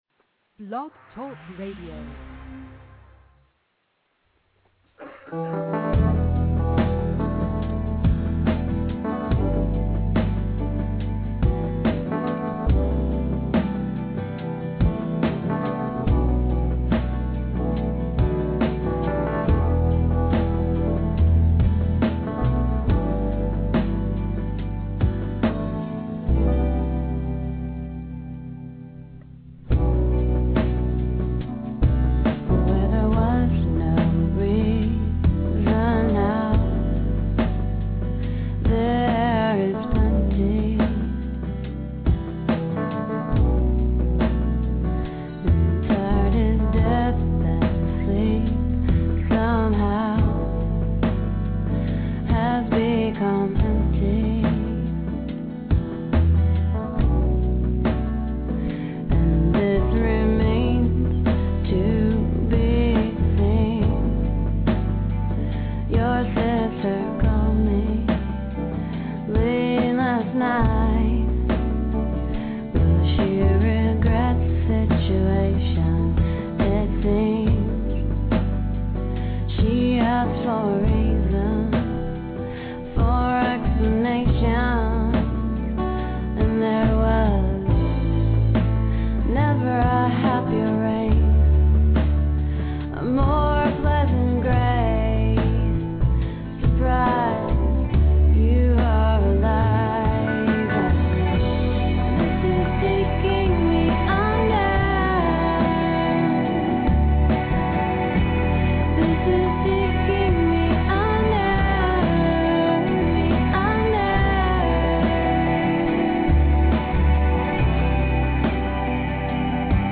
Under Interview